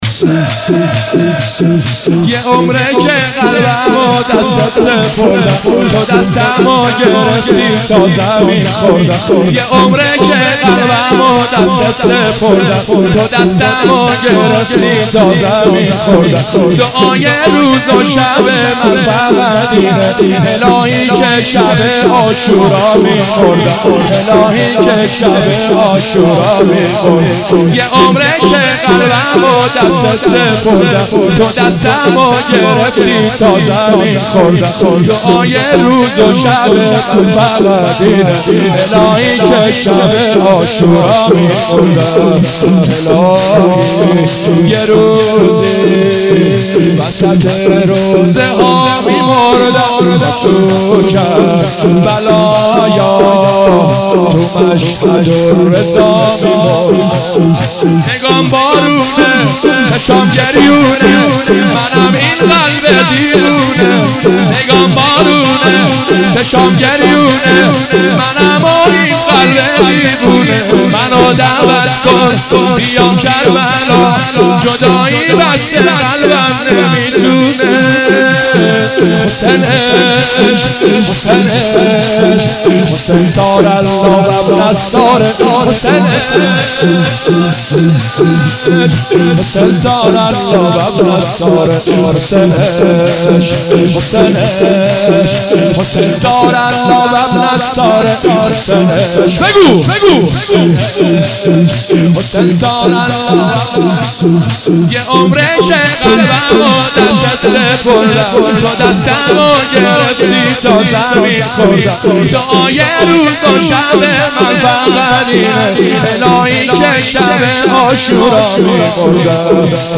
مناجات امام زمان